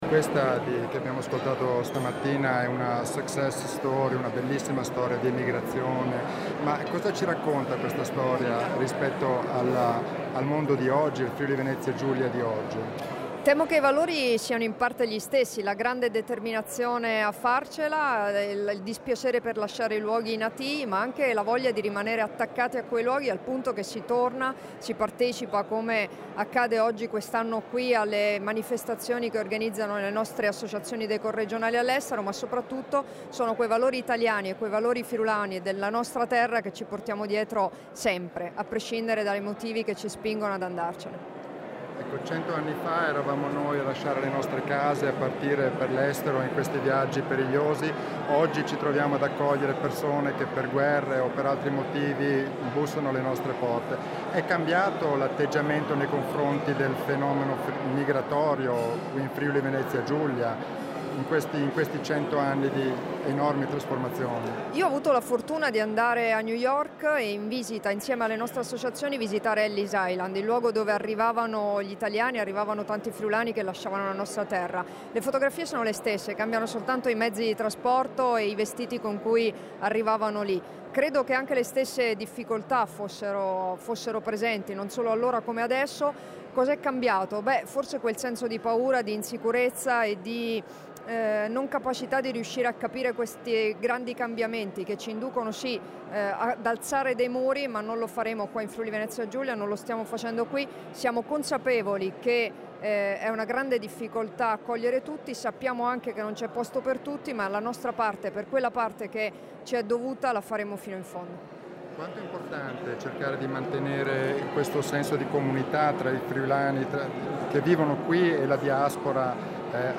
Dichiarazioni di Debora Serracchiani (Formato MP3) [2540KB]
a conclusione della tre giorni organizzata in Val Colvera dall'Ente Friulano di Assistenza Sociale e Culturale Emigranti (EFASCE), rilasciate a Poffabro il 31 luglio 2016